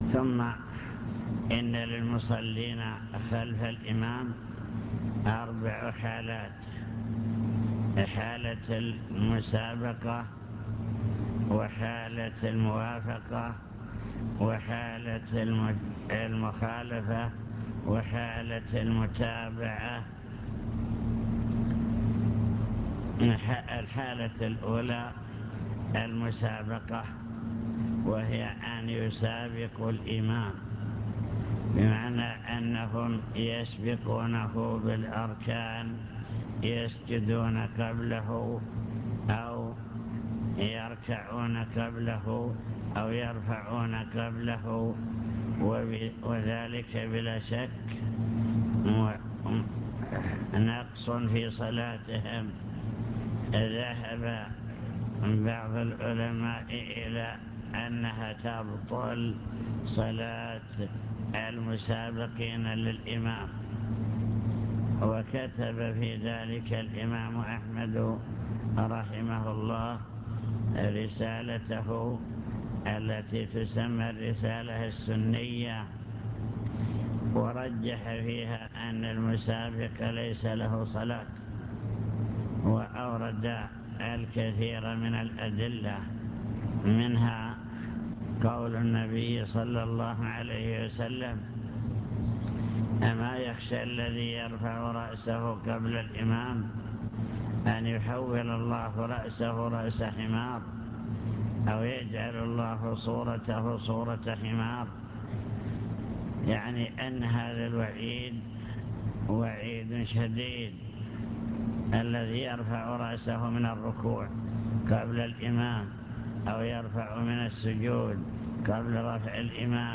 المكتبة الصوتية  تسجيلات - محاضرات ودروس  محاضرة في بدر بعنوان: وصايا عامة حالات المصلين خلف الإمام